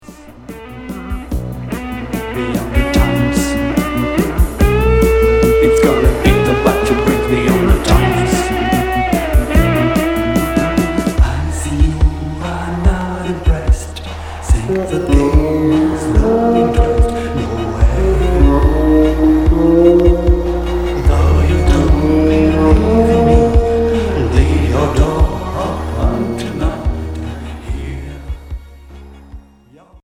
Pop new wave expérimentale